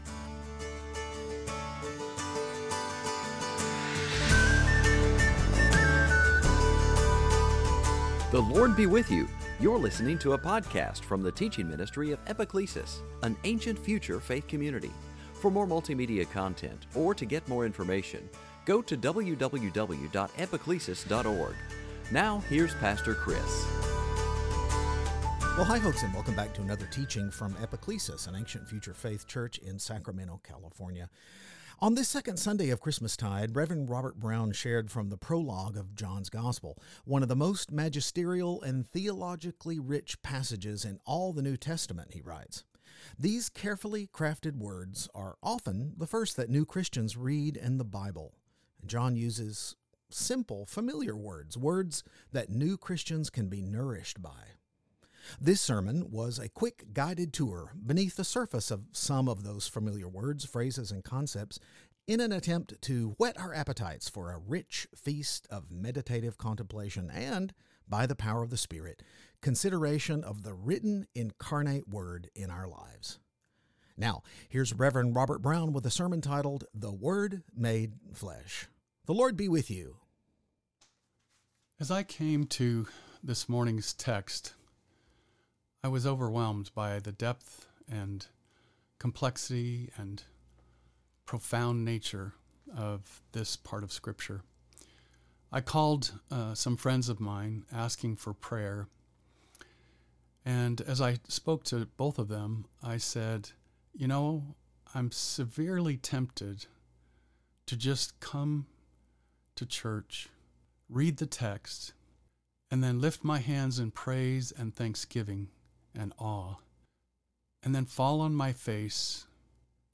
This sermon is a quick guided tour beneath the surface of some of those familiar words, phrases, and concepts in an attempt to whet our appetites for a rich feast of meditative contemplation and–by the power of the Spirit–consideration of the written incarnate Word in our own lives.